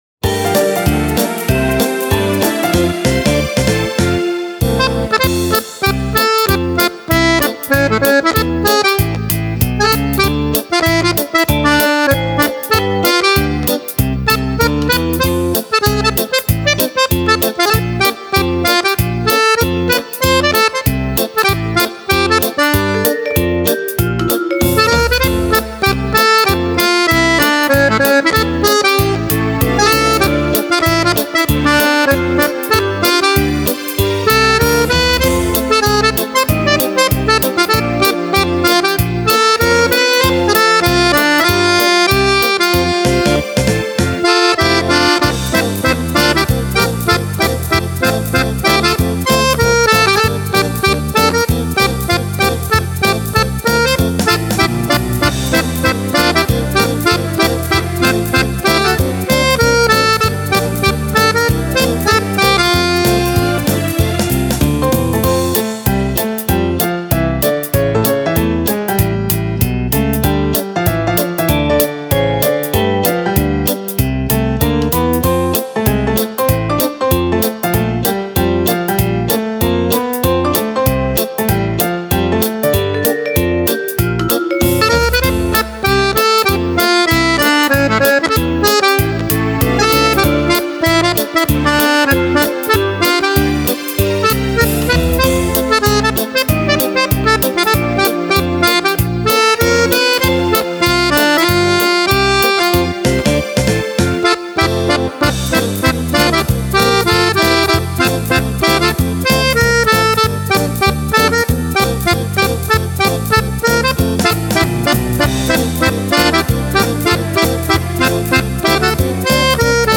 Fox - trot